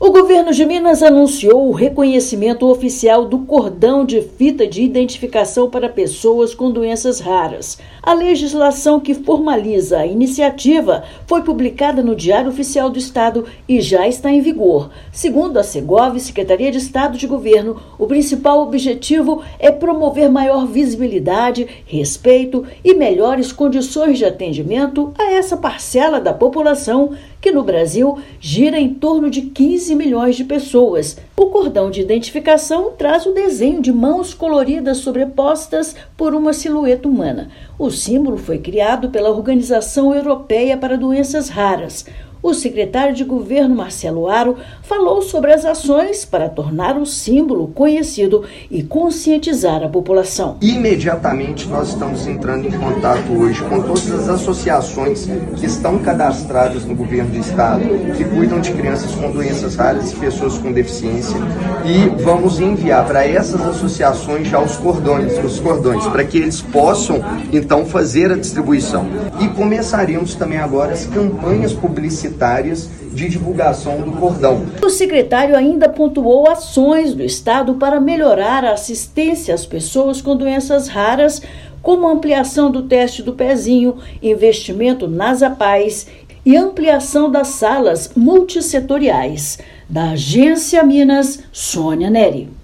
Medida visa a facilitar e melhorar o atendimento a essa parcela da população, proporcionando respeito e qualidade de vida. Ouça matéria de rádio.